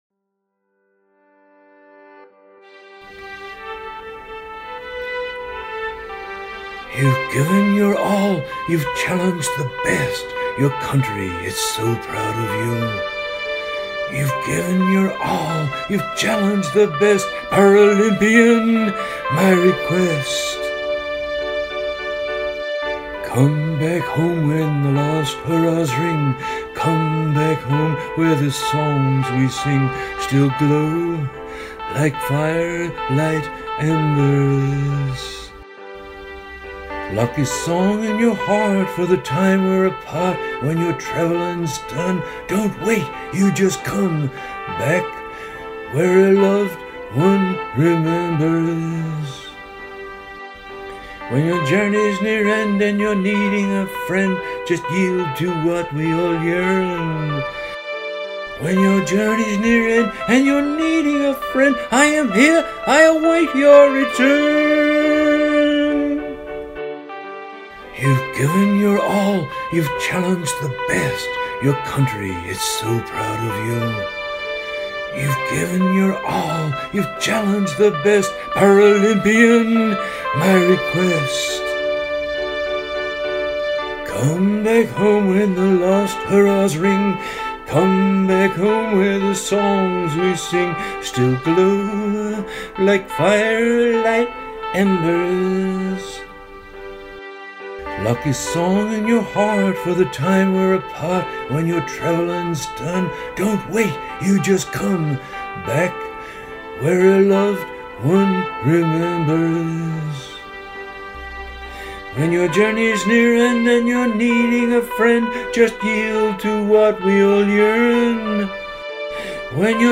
CBH_final PARA vocal.mp3